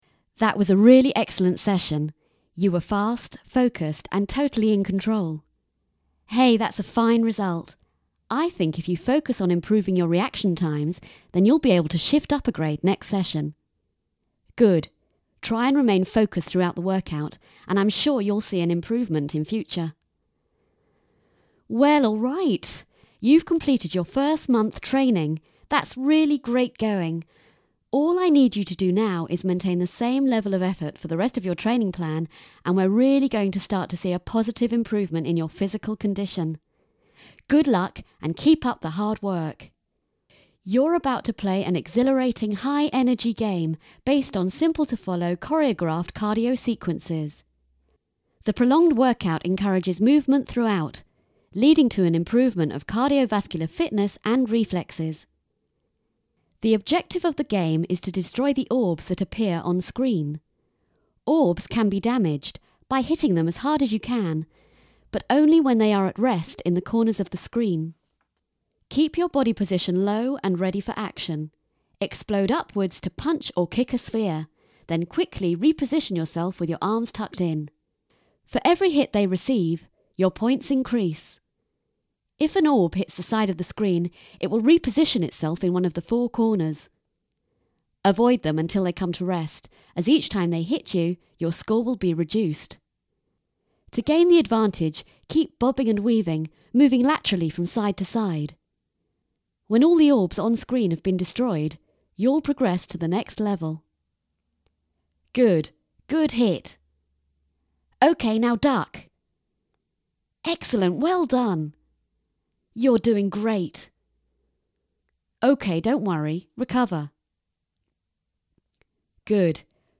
She is also at home with most regional accents.
She specialises in teenage and young voices and has a ‘deliciously older sexy read’!
• Female